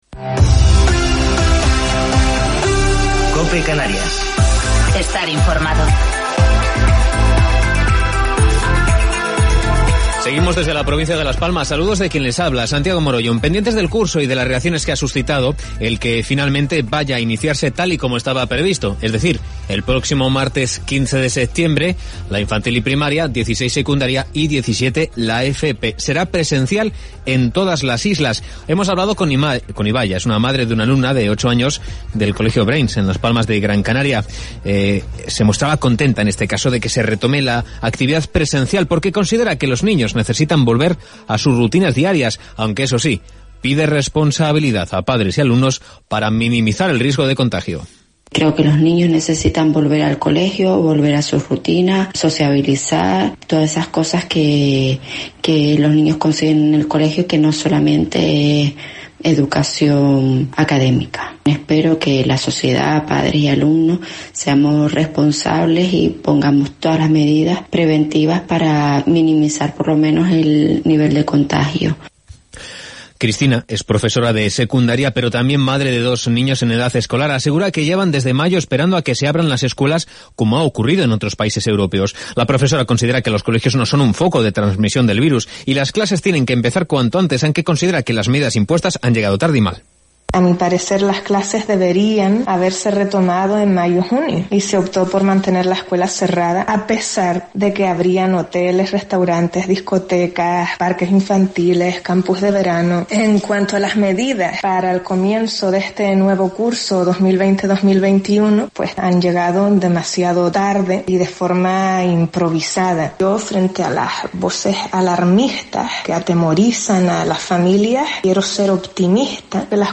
Gran Canaria Informativo local